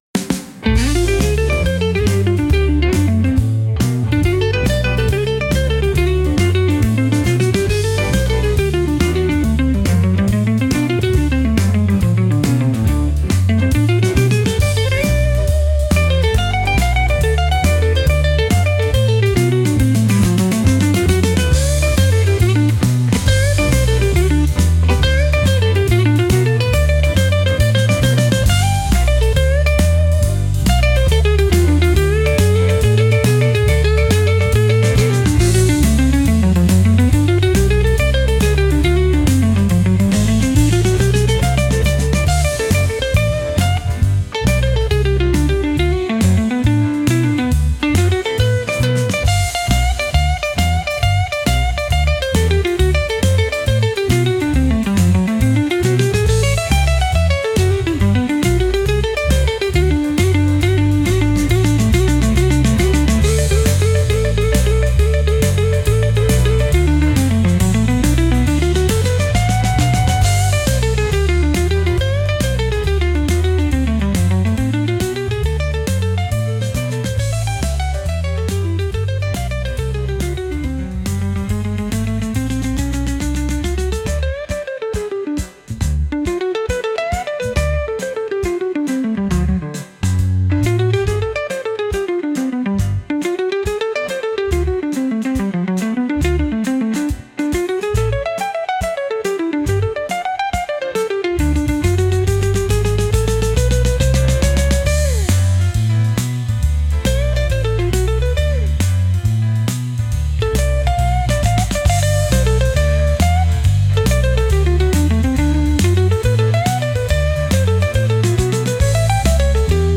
Instrumental - RLMradio Dot XYZ - 2.14.mp3